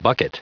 Prononciation du mot bucket en anglais (fichier audio)